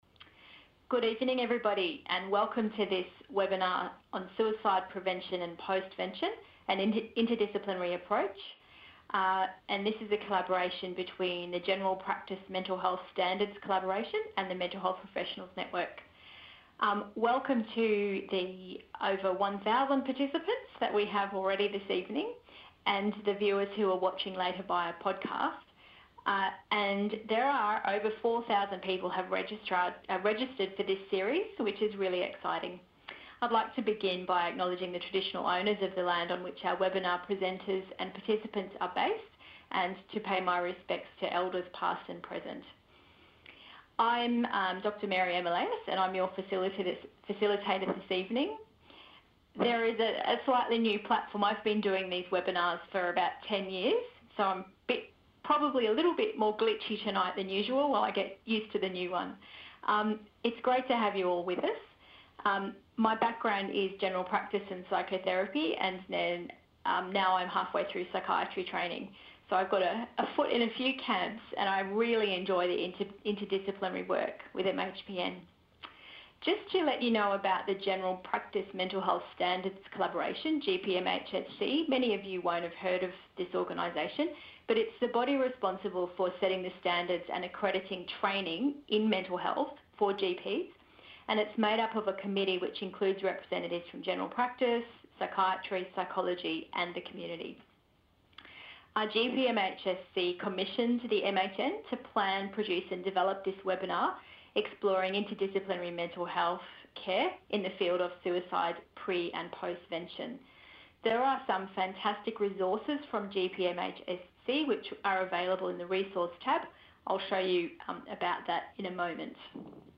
The General Practice Mental Health Standards Collaboration (GPMHSC) partners again with MHPN for a second webinar that will cover suicide prevention and postvention in a facilitated inter-disciplinary panel discussion.